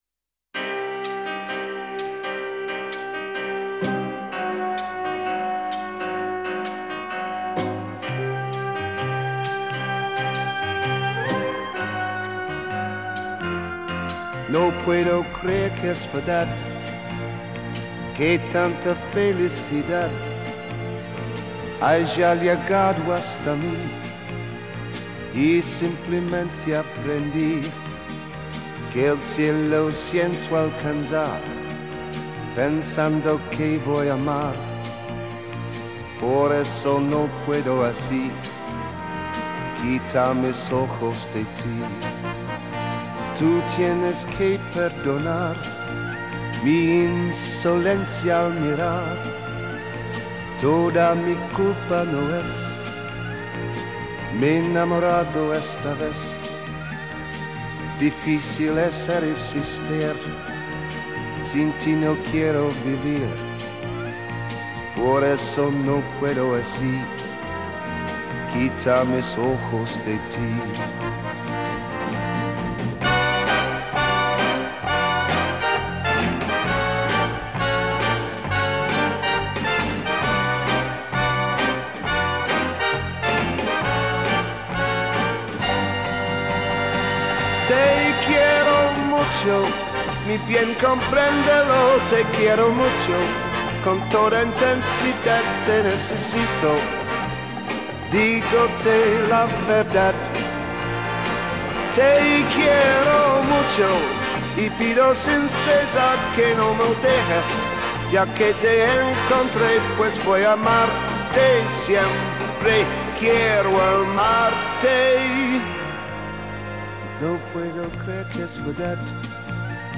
Una melodía romántica para este miércoles
cantante británico, con un gran sentido del swing